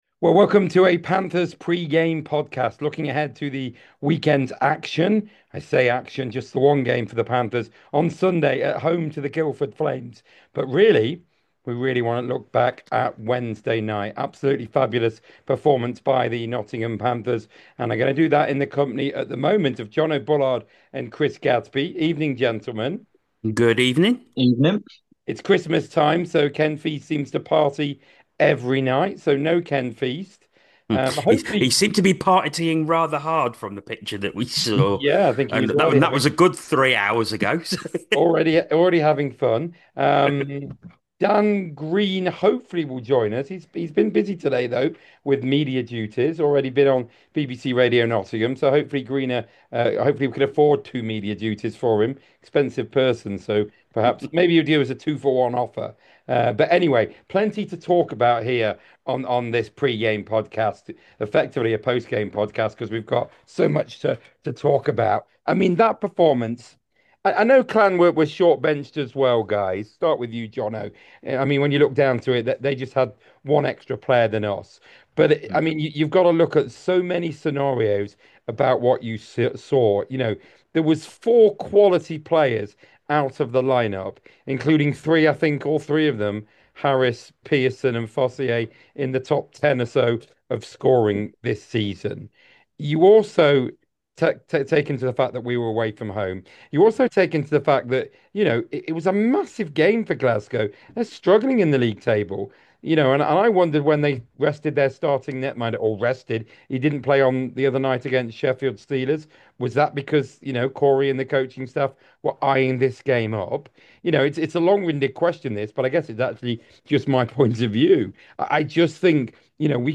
The Nottingham Panthers Pre-Game Podcast is back for the weekend and the crew chat about all things Panthers related.